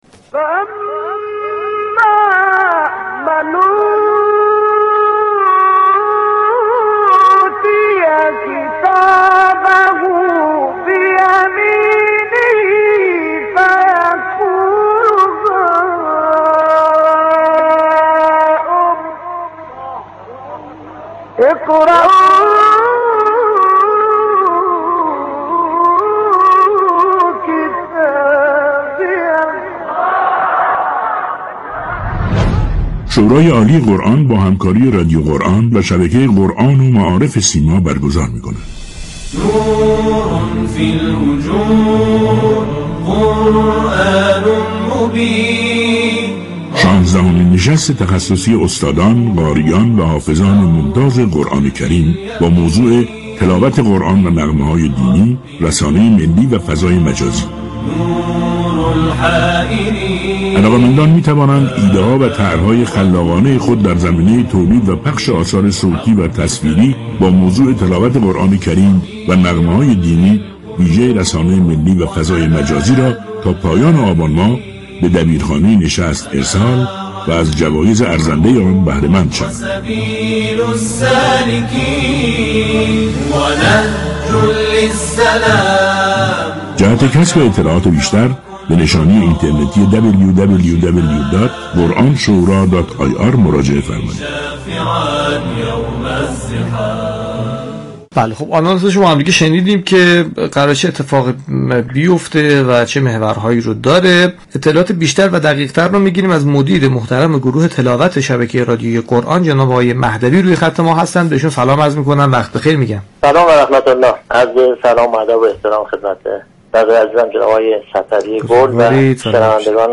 در گفتگو با برنامه والعصر